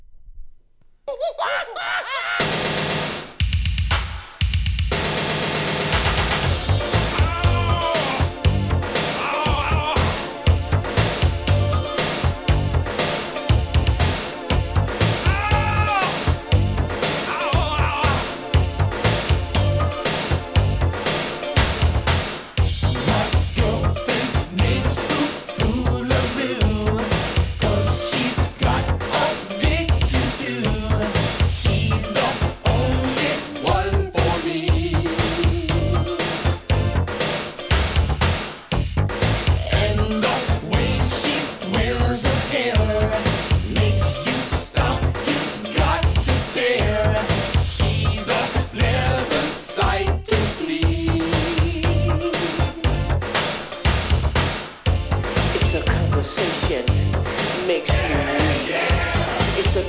店舗 数量 カートに入れる お気に入りに追加 1987年、エレクトロFUNK!